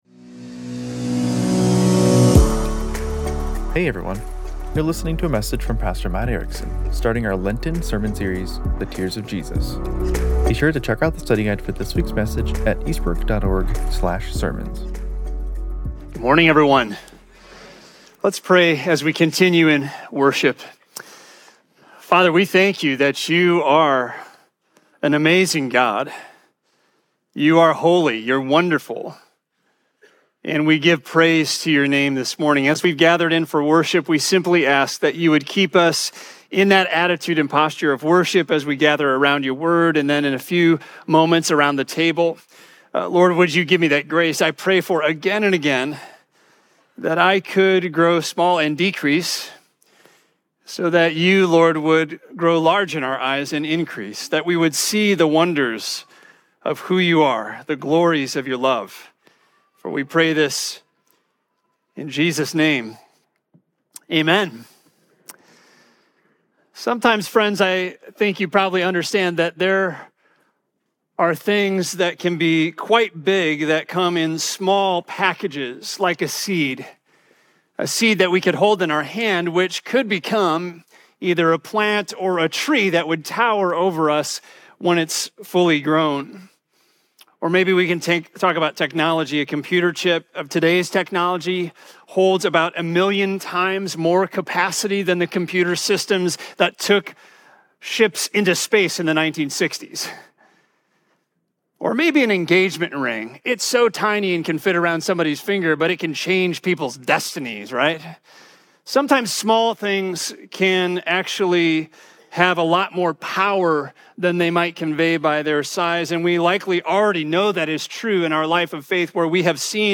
John 11:1-44 Sermon Outline “Jesus wept.”